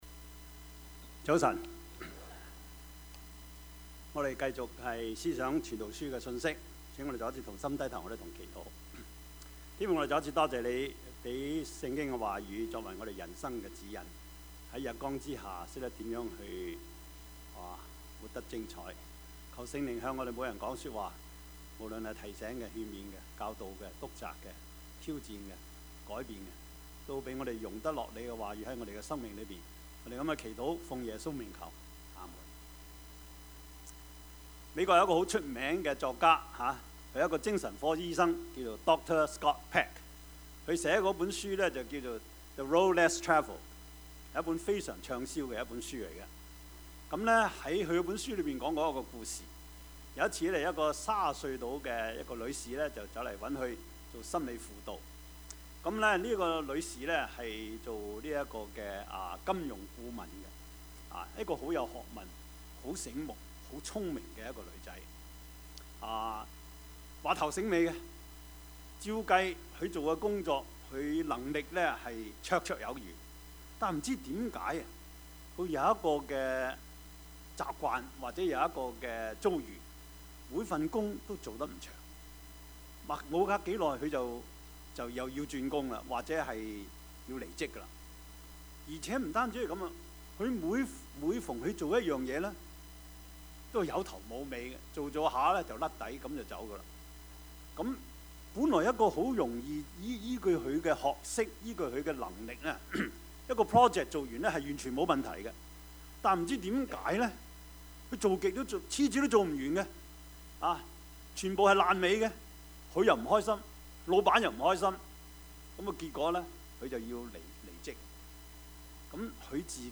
Service Type: 主日崇拜
Topics: 主日證道 « 假的真不了 蔡元培 »